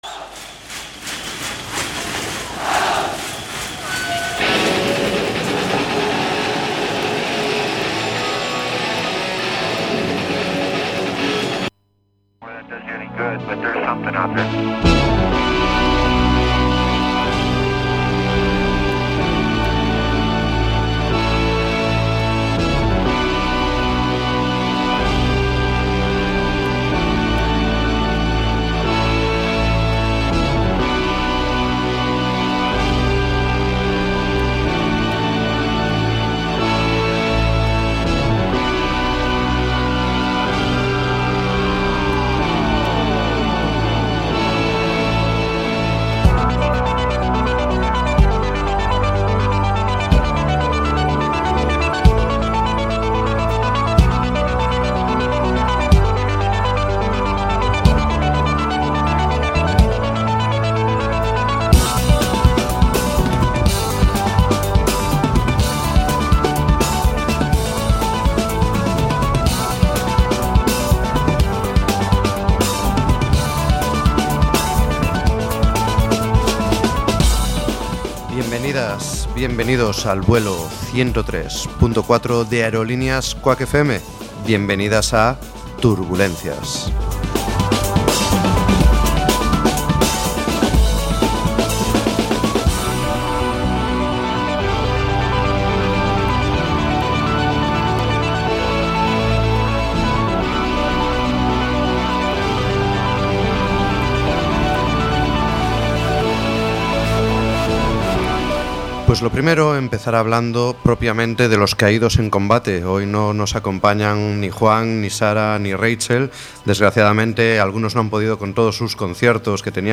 Turbulencias es un Magazine musical que abarca la actualidad musical a través de noticias, entrevistas, agenda y monográficos además de otras muchas secciones.